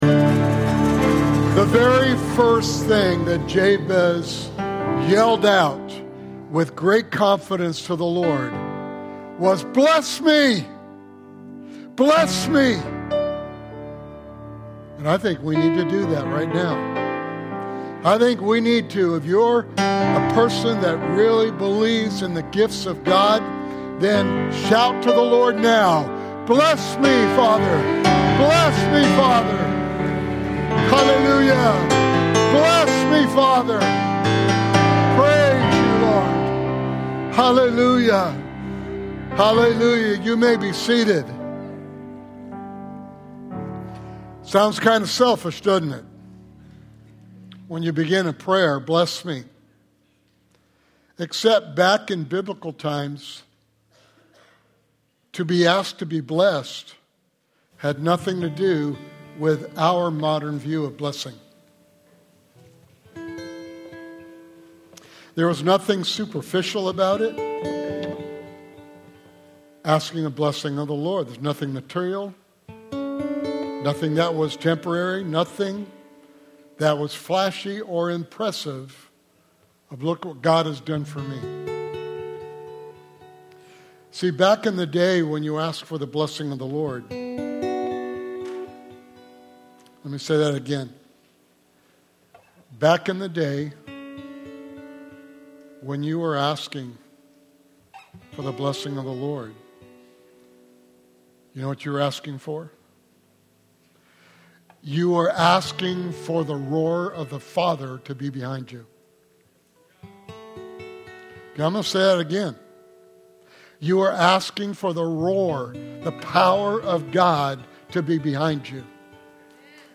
Sunday morning sermon
Sermon Series: God Given Benefits/Gifts